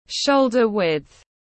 Rộng ngang vai tiếng anh gọi là shoulder-width, phiên âm tiếng anh đọc là /ˈʃəʊl.dər wɪtθ/ .